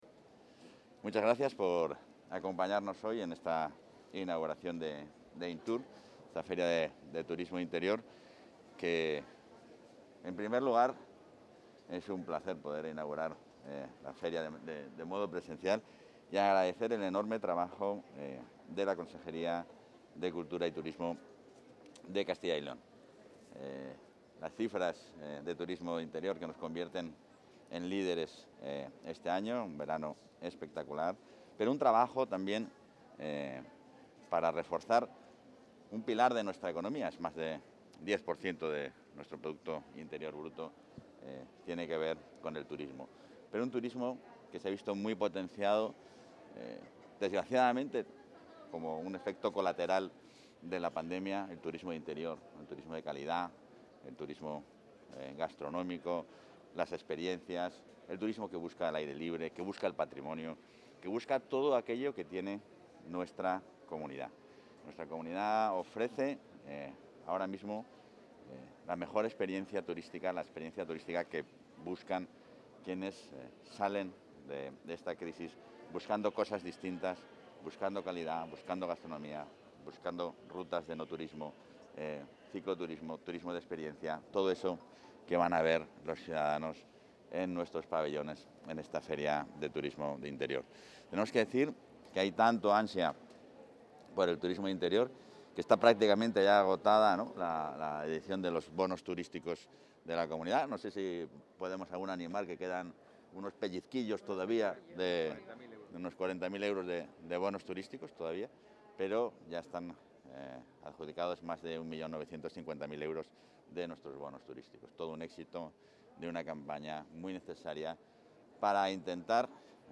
El vicepresidente Igea inaugura INTUR 2021, en la Feria de Muestras de Valladolid
Declaraciones del vicepresidente de la Junta.